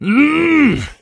XS蓄力02.wav
XS蓄力02.wav 0:00.00 0:01.01 XS蓄力02.wav WAV · 87 KB · 單聲道 (1ch) 下载文件 本站所有音效均采用 CC0 授权 ，可免费用于商业与个人项目，无需署名。
人声采集素材